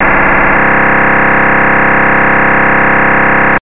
сигнал